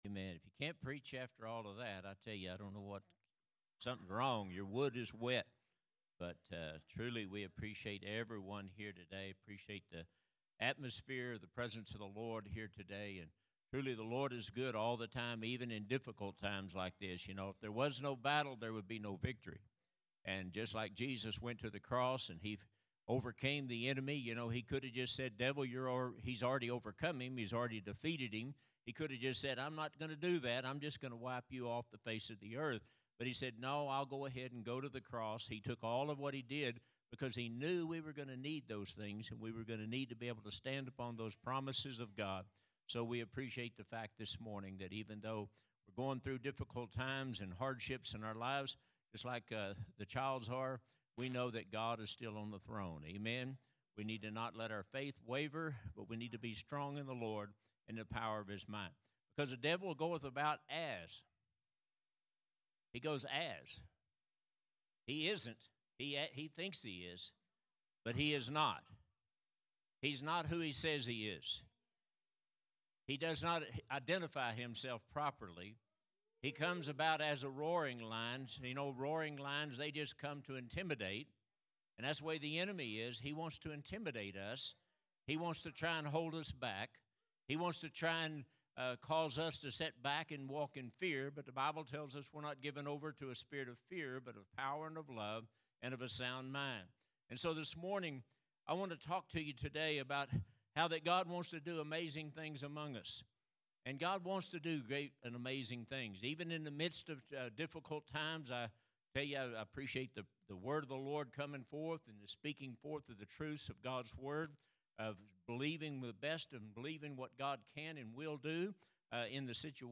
Sermons | Harvest Time Church